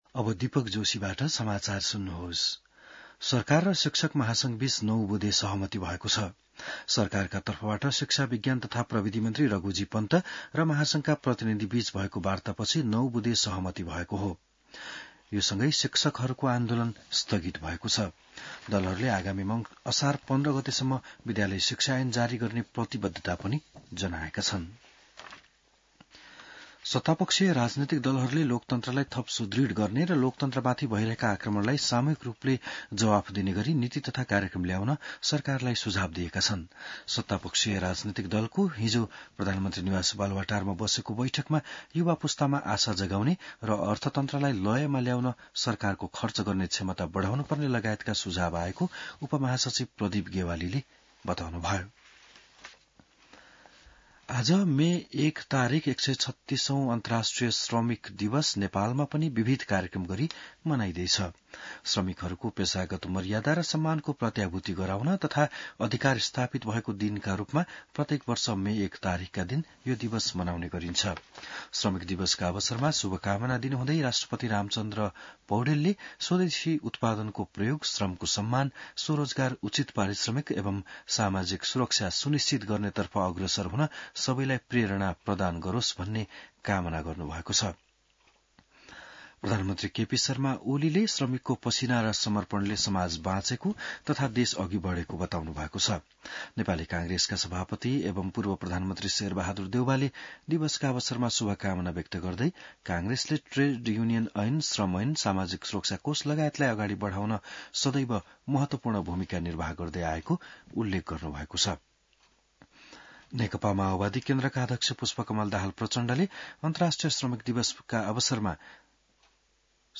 बिहान १० बजेको नेपाली समाचार : १८ वैशाख , २०८२